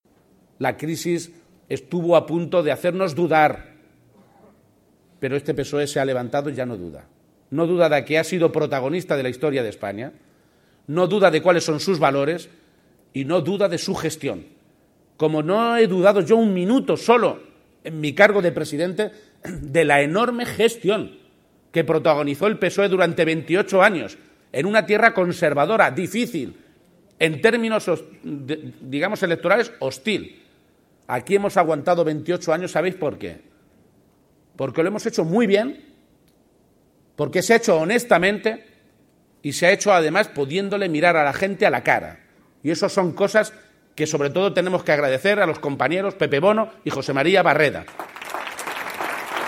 Indicar por último que García-Page ha realizado estas declaraciones en la inauguración del 17 Congreso Provincial del PSOE de Ciudad Real que consagrará a José Manuel Caballero como secretario general de los socialistas en esta provincia, acto en el que también han estado presentes la vicesecretaria general y portavoz del PSCM-PSOE, Cristina Maestre, los consejeros de Sanidad y de Economía, Empresas y Empleo, Jesús Fernández y Patricia Franco, respectivamente, el portavoz del Gobierno regional, Nacho Hernando, parlamentarios nacionales encabezados por ex presidente José María Barreda, diputados regionales y provinciales, así como una amplia representación de alcaldes y portavoces, organizaciones sociales, empresariales y sindicales.
Cortes de audio de la rueda de prensa